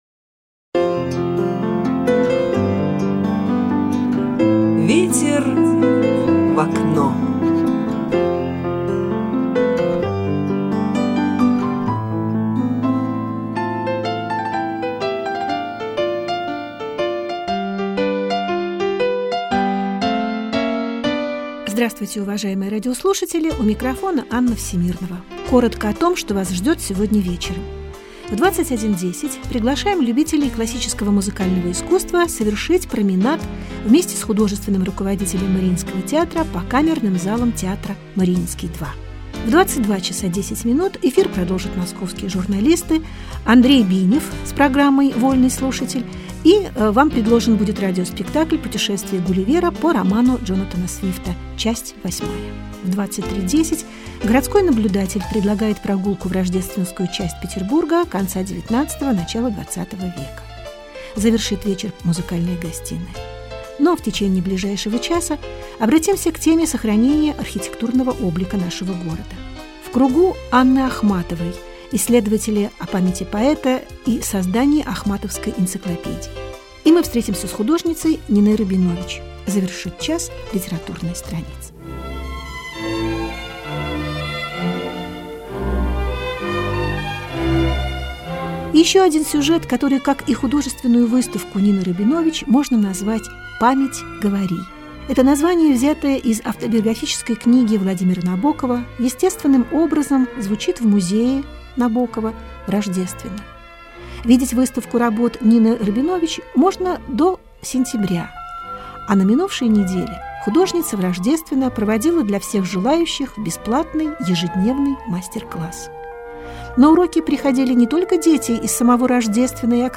Интервью в программе " Ветер в окно" Радио России в Петербурге
Здесь вы найдёте два интервью, записанные для передачи по Радио Россия во время моего пребывания в Петербурге в ходе рабочих стипендий в 2009 и 2014 годах